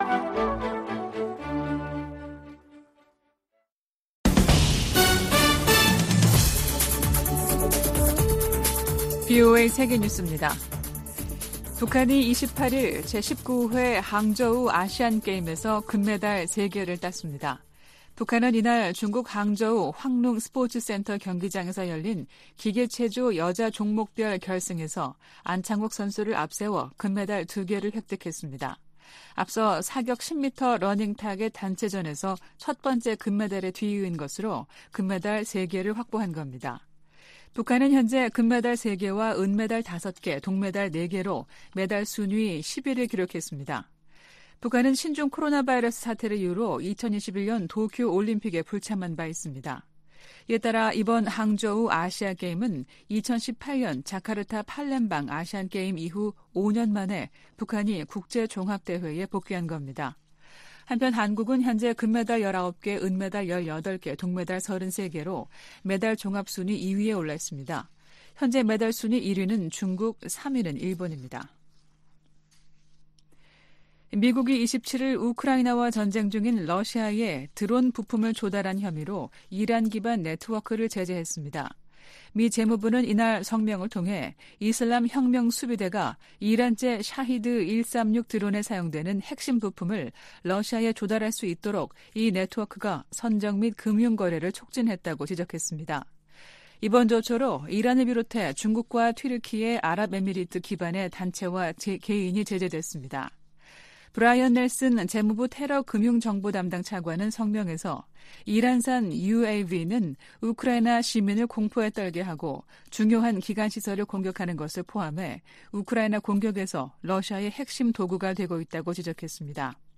VOA 한국어 아침 뉴스 프로그램 '워싱턴 뉴스 광장' 2023년 9월 29일 방송입니다. 미 국무부는 트래비스 킹 이병의 귀환이 북한 문제 해결을 위한 외교 재개로 이어지긴 어려울 것이라는 입장을 밝혔습니다. 제 78차 유엔총회 일반토의 마지막날, 한국은 한반도 긴장의 원인을 한국과 미국에 돌린 북한측 발언을 근거없고 비논리적이며 터무니 없는 주장이라고 비판했습니다. 북한이 핵 무력 강화를 국가의 기본 방향으로 헌법에 명시했습니다.